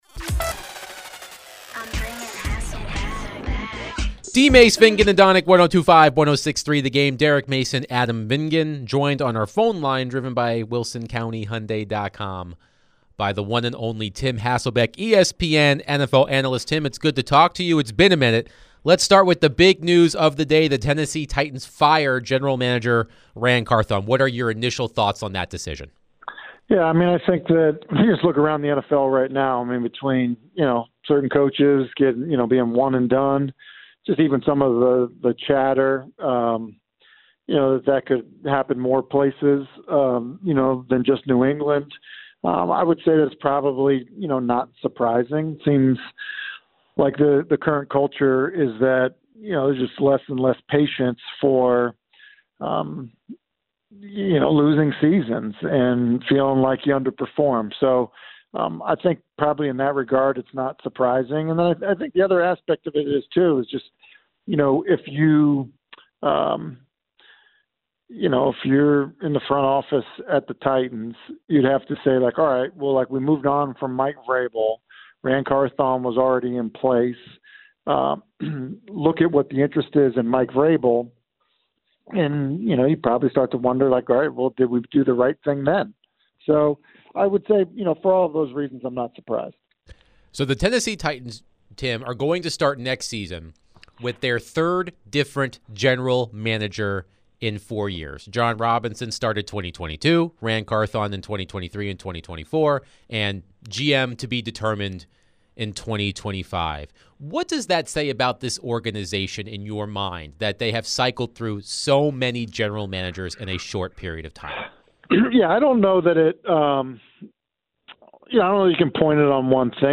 ESPN Analyst Tim Hasselbeck joined DVD to discuss the Titans firing GM Ran Carthon, NFL News and more